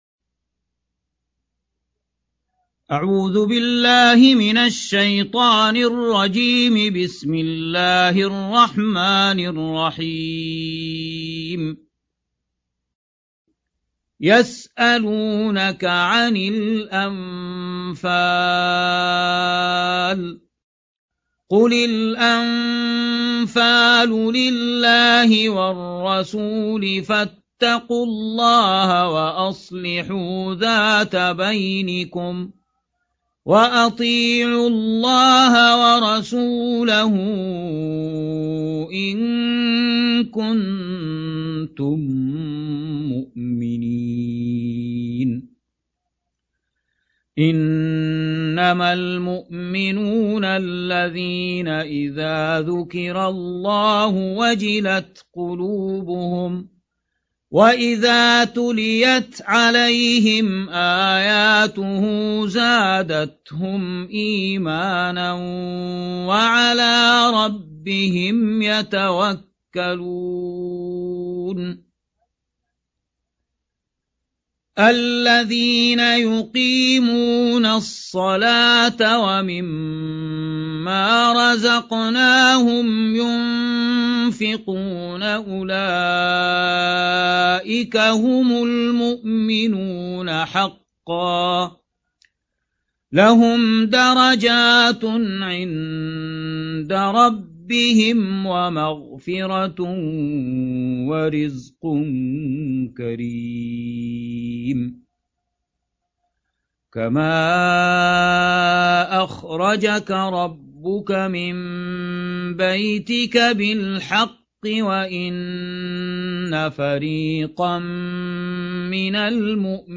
8. سورة الأنفال / القارئ